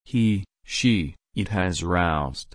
Past simple
/ˈɹaʊzd/